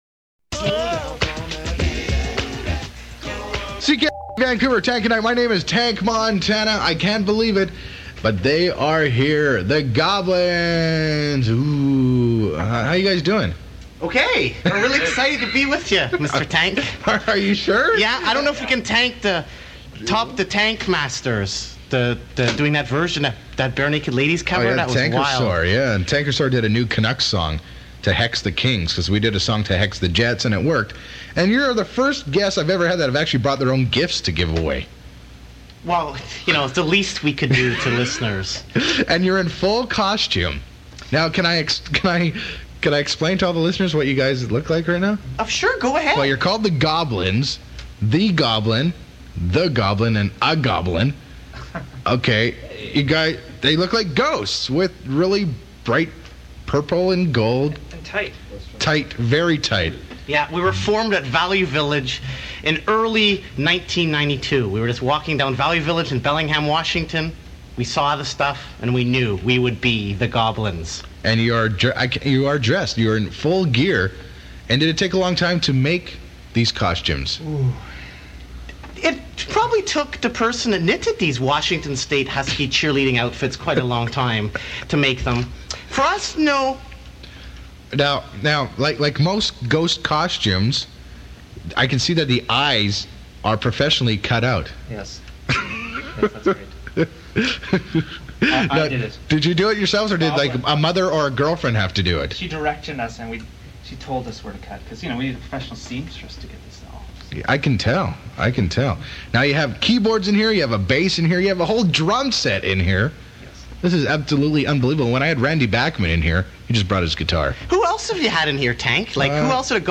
Live Set: